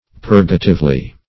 purgatively - definition of purgatively - synonyms, pronunciation, spelling from Free Dictionary Search Result for " purgatively" : The Collaborative International Dictionary of English v.0.48: Purgatively \Pur"ga*tive*ly\, adv. In a purgative manner.